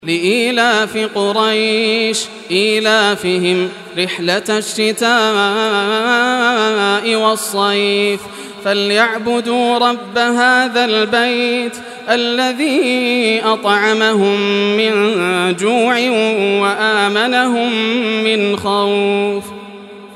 Surah Quraysh Recitation by Yasser al Dosari
Surah Quraysh, listen or play online mp3 tilawat / recitation in Arabic in the beautiful voice of Sheikh Yasser al Dosari.